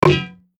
Golf_Hit_Barrier_2.ogg